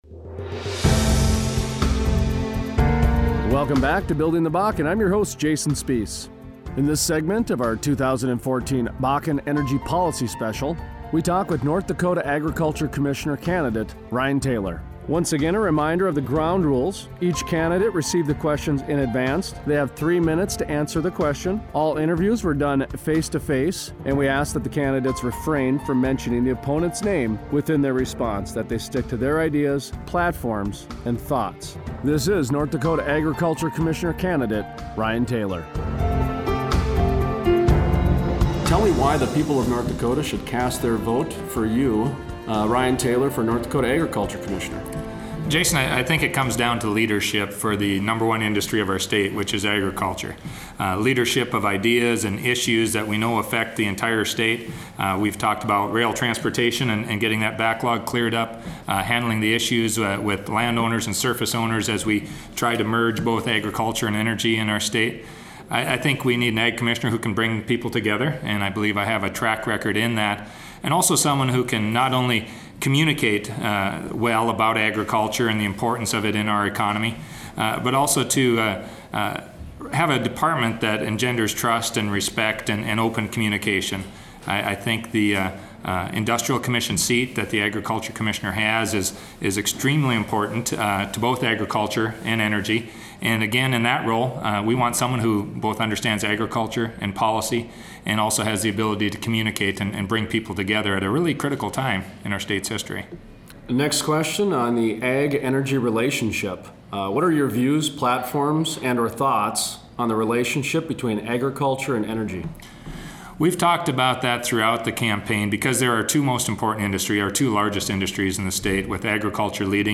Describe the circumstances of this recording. All interviews were conducted in person.